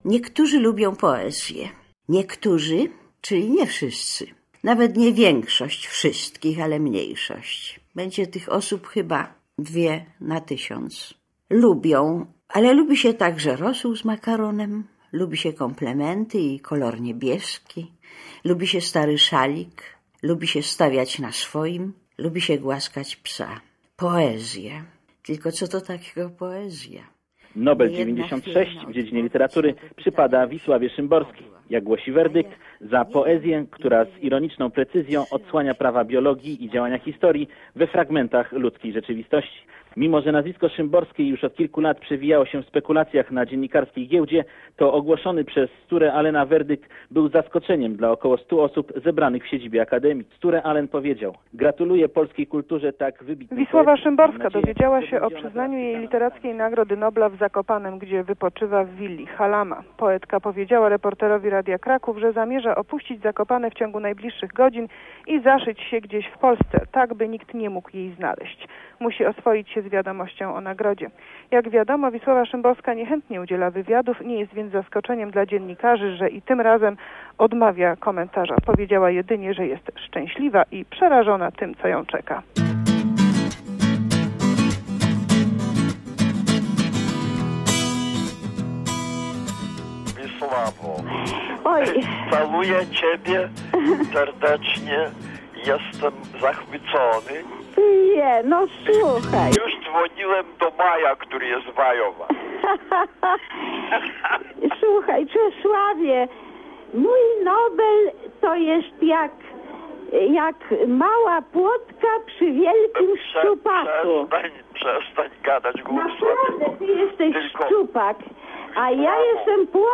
Wisława Szymborska w archiwalnych migawkach Radia Merkury.